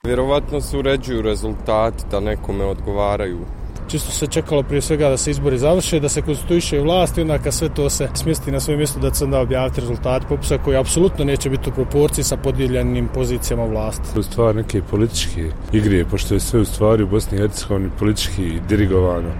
Građani Sarajeva o popisu